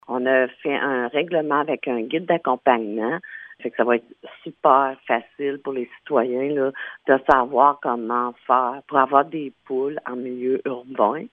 Comme l’indique la mairesse, Francine Fortin, les permis seront accompagnés d’un guide visant à encadrer la garde de poules en milieu urbain :